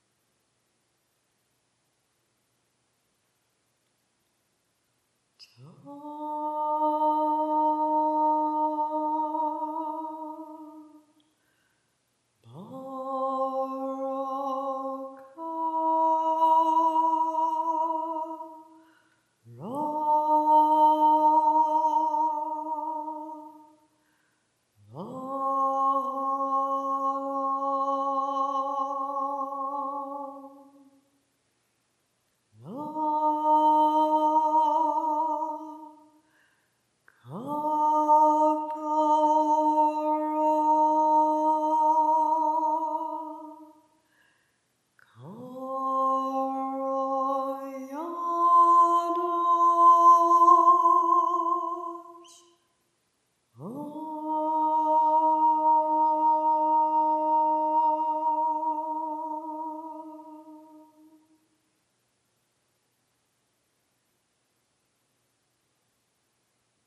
Healing Chants
My gifts to you- authentic sacred expressions of my healing voice.